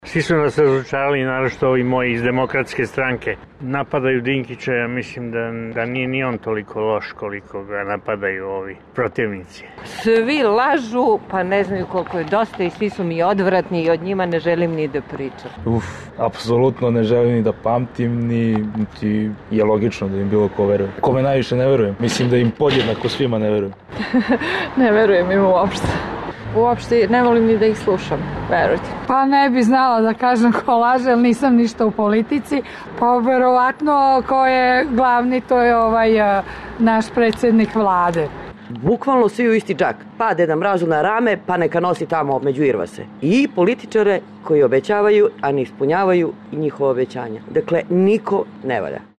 Anketa o nedoslednosti srpskih političara